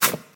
step-1.ogg.mp3